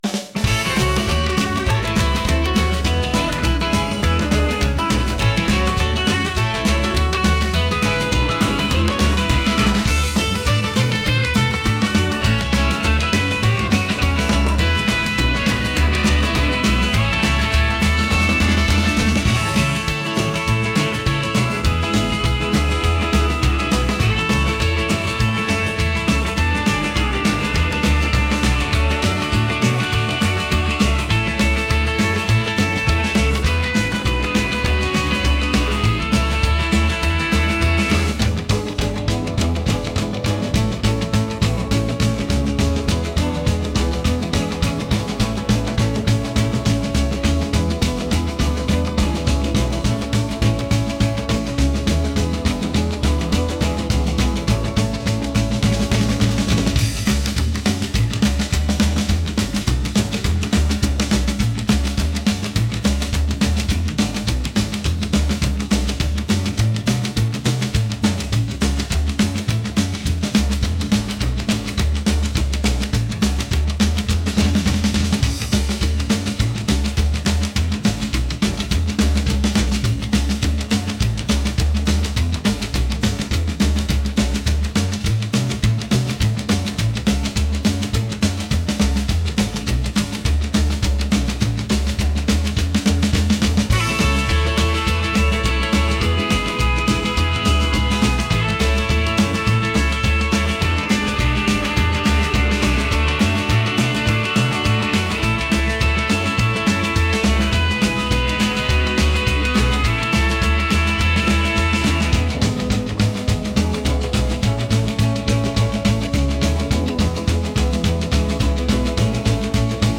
upbeat | energetic